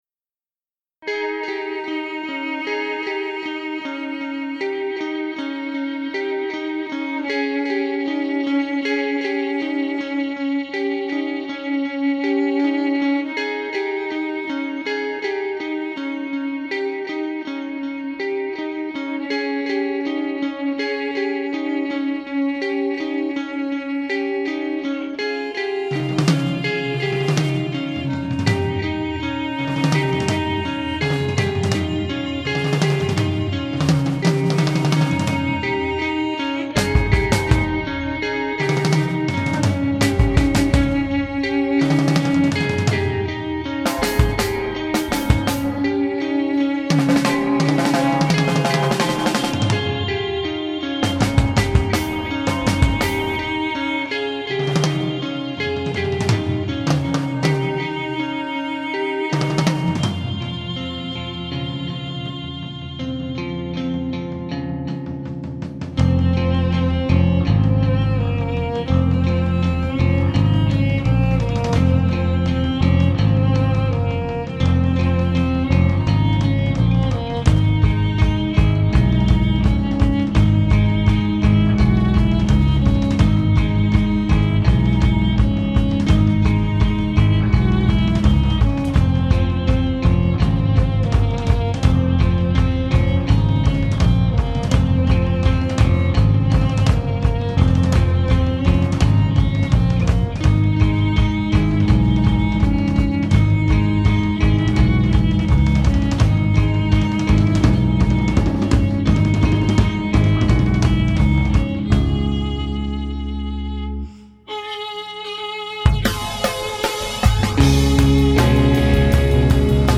It was a 4 piece all female band, except for me.